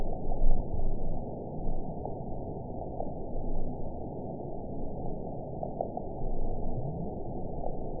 event 921749 date 12/18/24 time 19:26:24 GMT (11 months, 2 weeks ago) score 8.43 location TSS-AB03 detected by nrw target species NRW annotations +NRW Spectrogram: Frequency (kHz) vs. Time (s) audio not available .wav